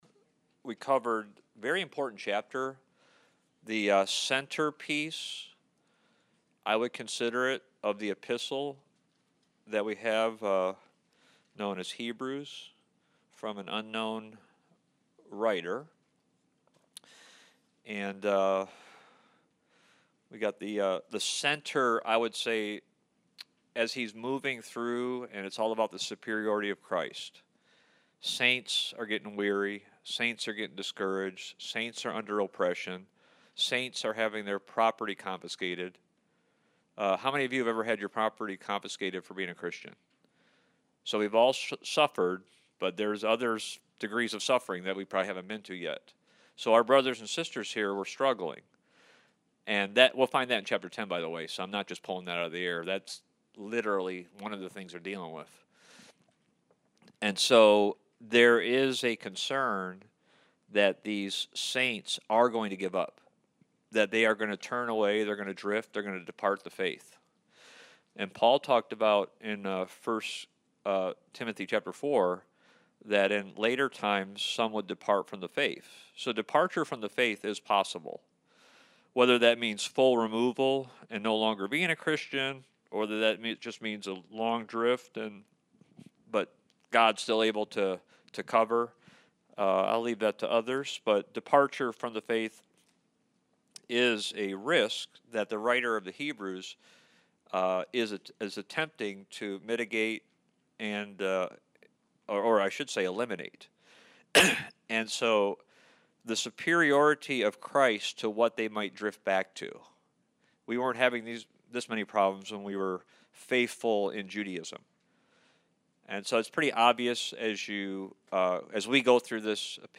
Series: Study of Hebrews Service Type: Wednesday Night